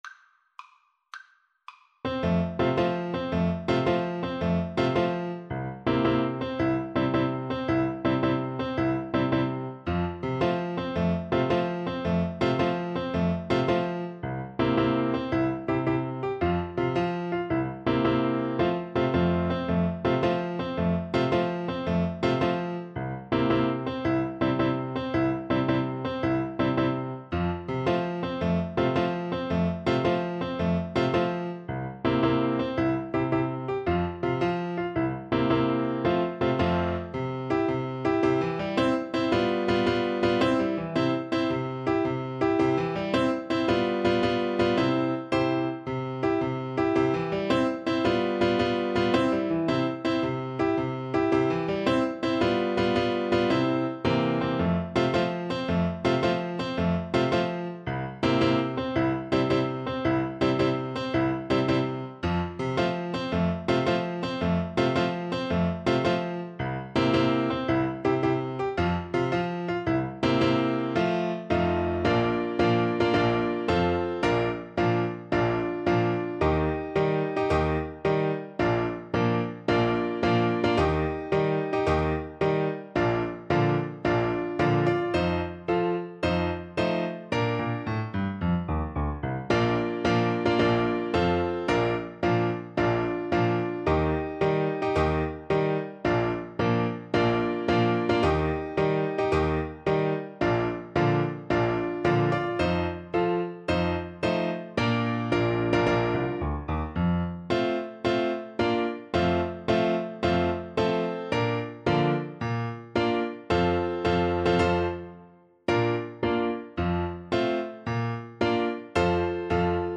F major (Sounding Pitch) (View more F major Music for Flute )
6/8 (View more 6/8 Music)
Classical (View more Classical Flute Music)